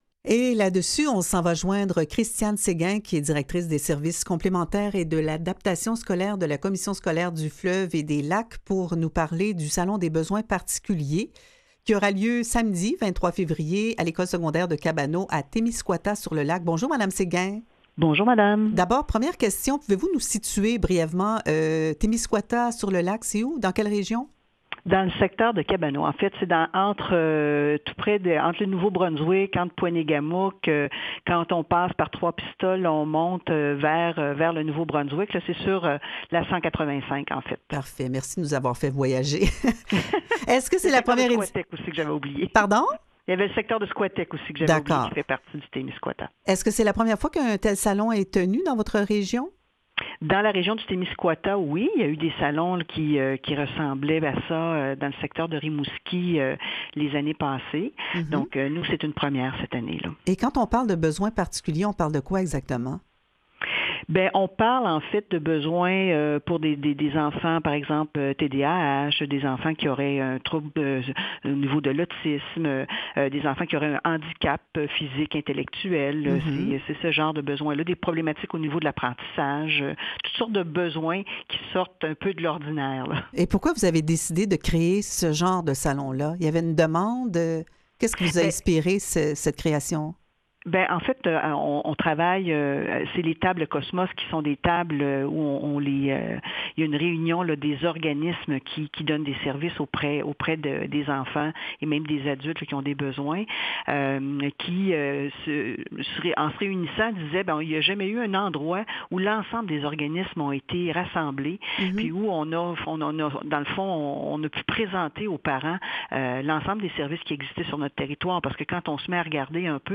En entrevue: